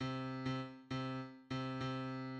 The "three-side" of clave
As used in Cuban popular music, tresillo refers to the "three-side" (first three strokes) of the son clave pattern.[a]